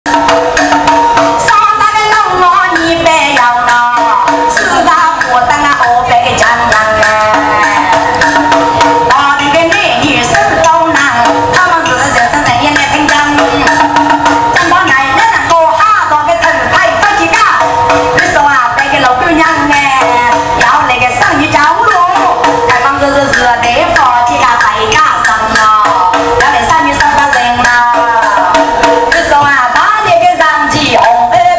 Song performed durring a Temple Fest in Shuixin suburb
Temple song.WAV